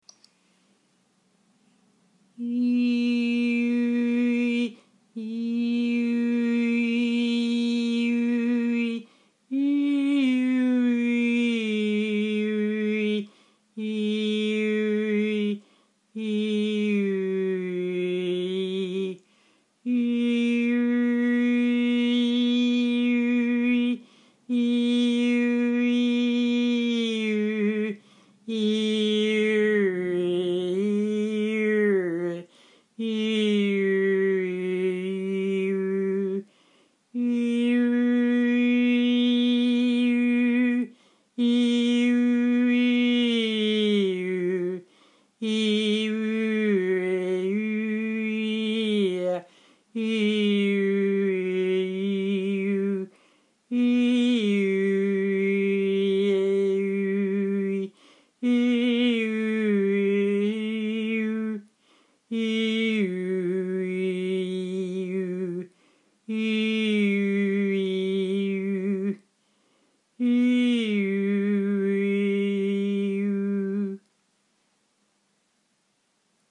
Tag: 声乐 部落 萨满祭祀 诵经